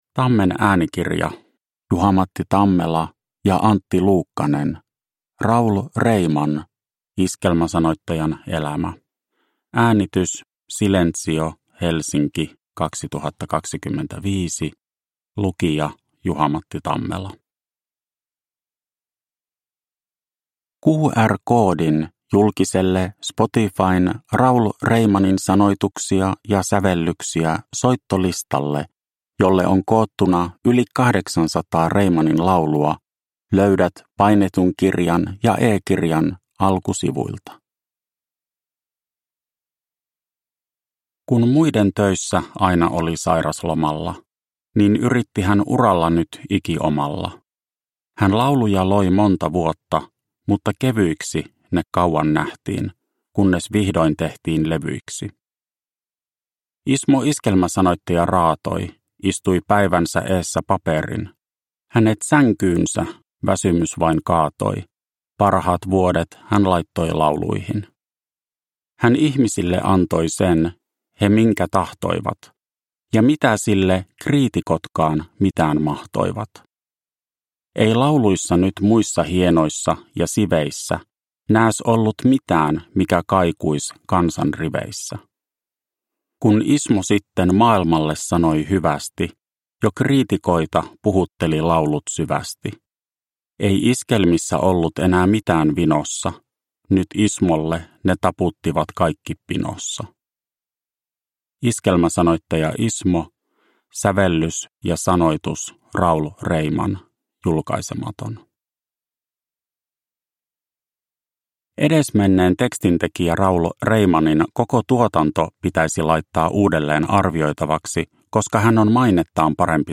Raul Reiman – Ljudbok